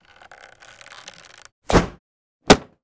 bow_sounds.wav